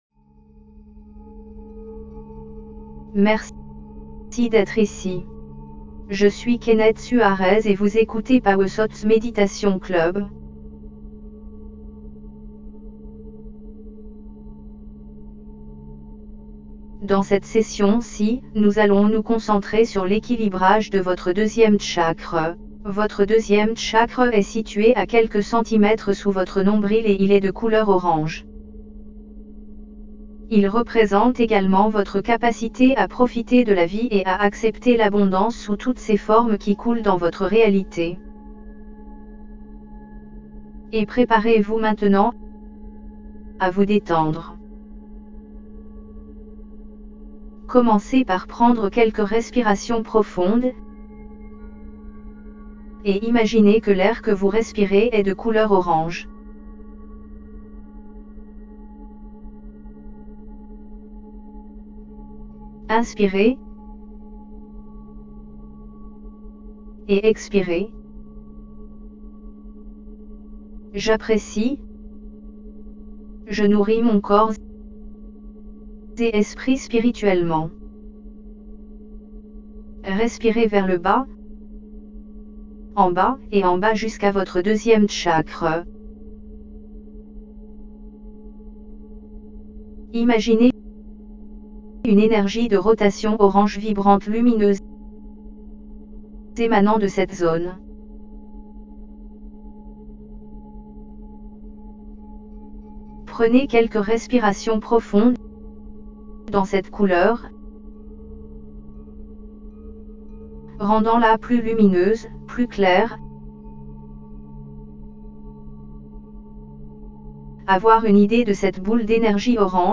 Activez le flux de qi de votre chakra sacré. Pour améliorer la guérison de votre méditation sur le chakra sacré, nous avons utilisé le solfège du chakra sacré 417 Hz.
2ActivatingQiFlowOfSacralChakraMeditationFR.mp3